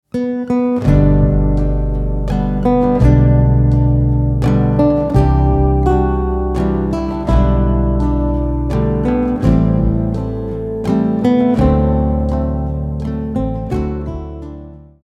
Backing Track 🎶